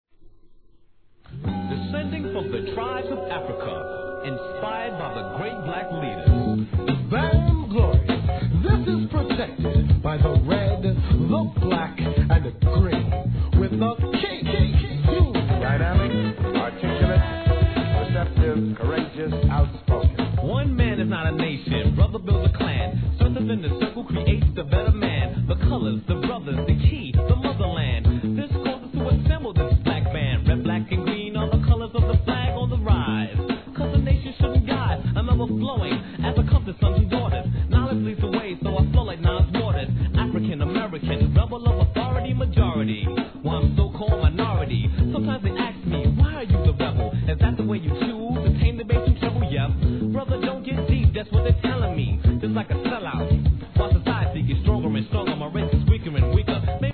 HIP HOP/R&B
カルト的なイメージが強いグループですが、思い切ったネタ使いといい、個性的なRAPといい、食わず嫌いは損!!